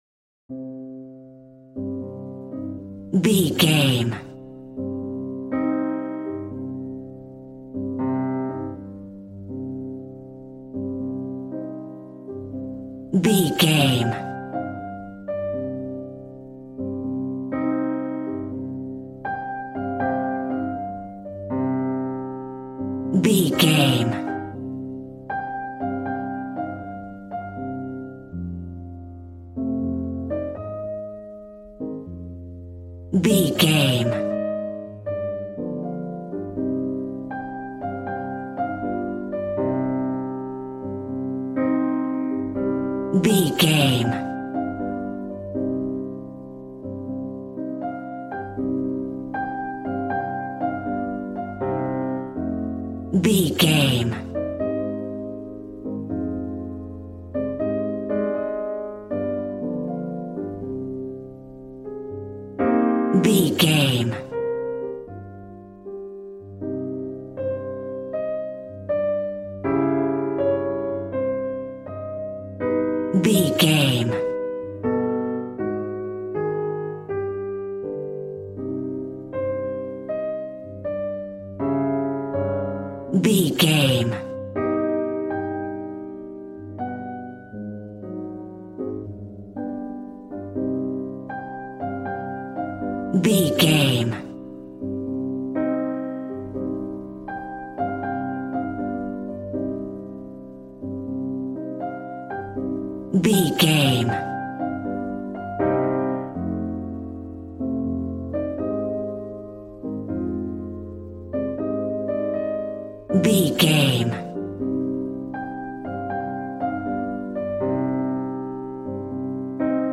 Smooth jazz piano mixed with jazz bass and cool jazz drums.,
Aeolian/Minor
piano
drums